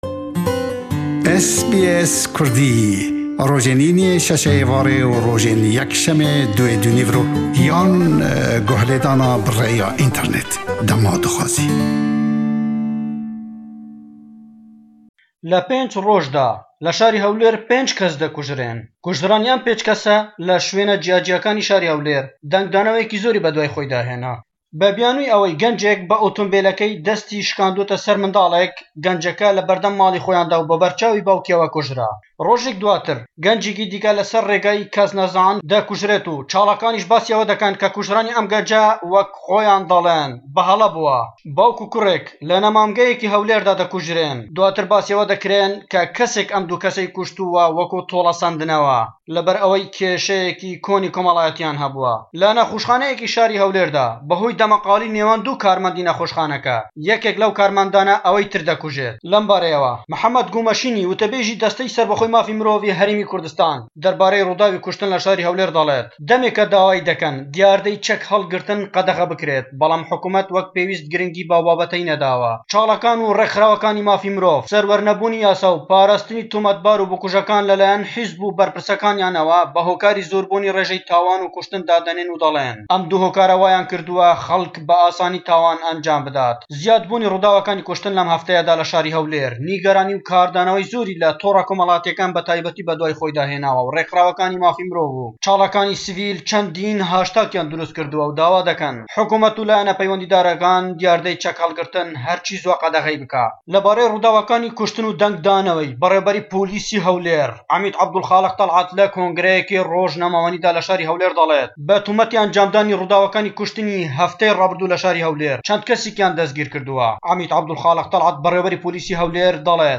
Be layenî kemewe pênc kes kujran paş jimareyek le rûdawî cûdayî kuştin le şarî Hewlêr, ke bû be hoyî derbirrînî nîgeran û dawakarî çalakwanan bo tundkirdinewey yasayî çek hellgirtin. Zortir le em raportey